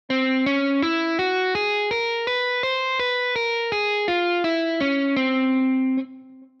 エニグマティック・スケール
フリジアの香りから始まり、中盤の全音差が連続する箇所はホールトーンスケールを思わせます。しかし最後のシがちゃんと半音差で主音に寄り添っているところは、ホールトーンとは違いますね。
m4-enigmatic-scale.mp3